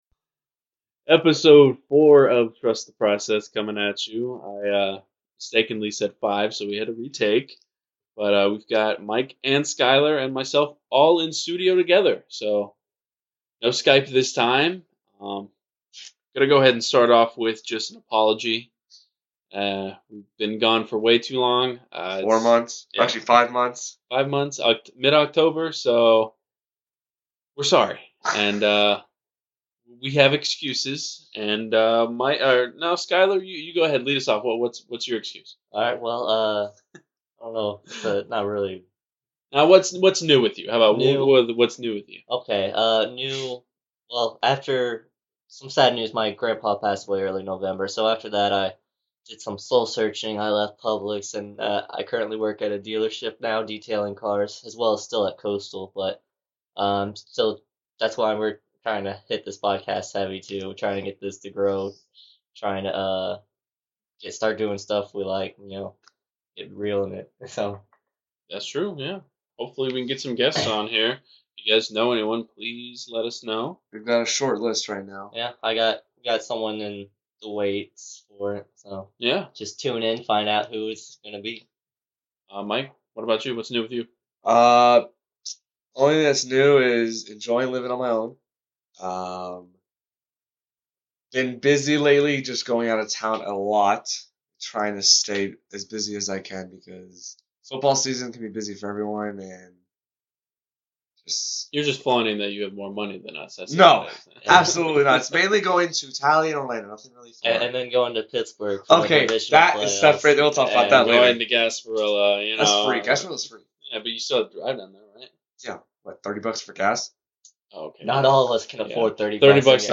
We're back in the studio! The boys get into what's new with each other, Jag's uniform thoughts, Jumbo Shrimp hats, Icemen, current events and college basketball.